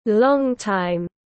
Thời gian dài tiếng anh gọi là long time, phiên âm tiếng anh đọc là /ˈlɒŋ.taɪm/